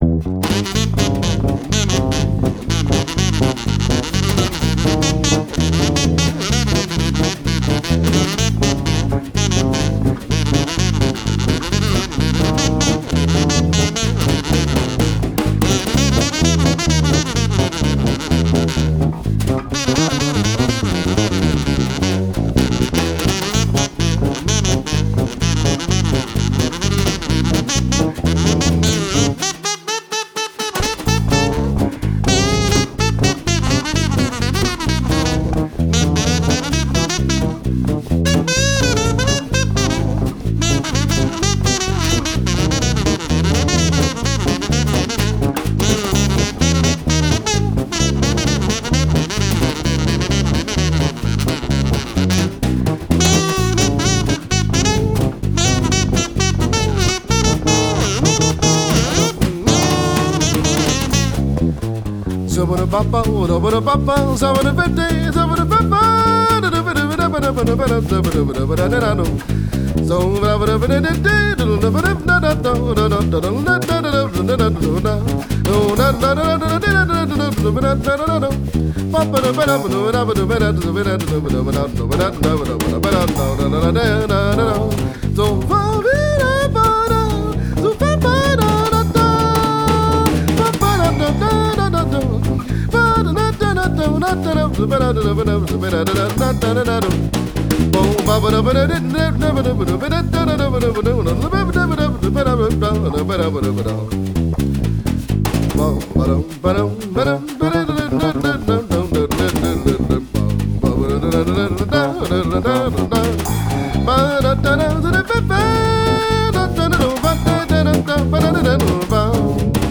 resonator bass
drums
drums/spoken word